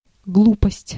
Ääntäminen
IPA: /ˈɡlupəsʲtʲ/